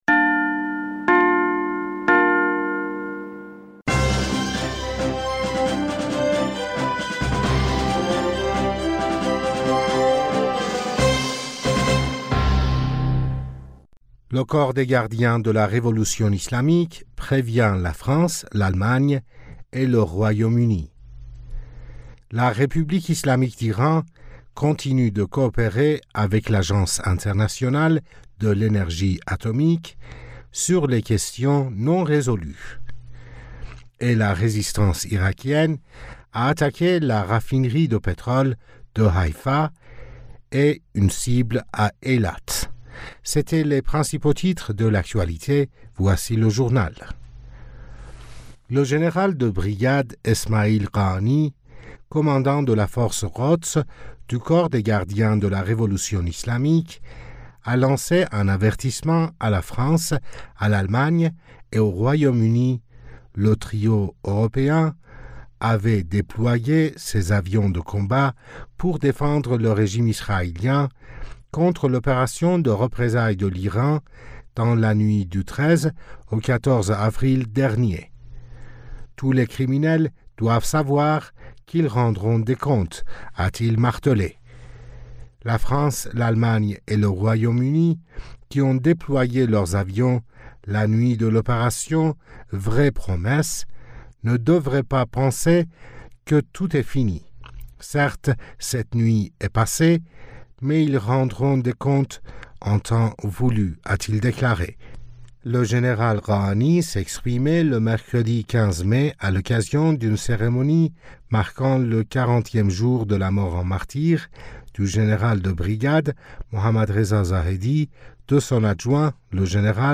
Bulletin d'information du 16 Mai